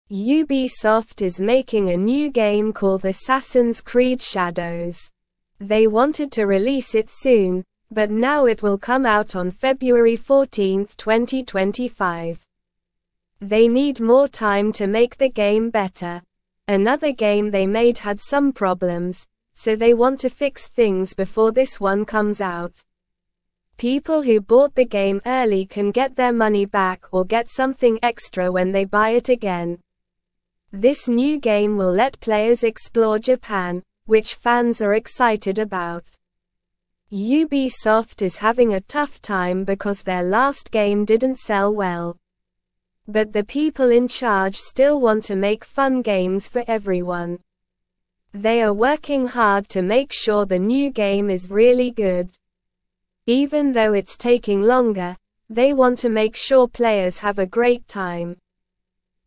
Slow
English-Newsroom-Beginner-SLOW-Reading-Ubisoft-delays-new-game-to-make-it-better.mp3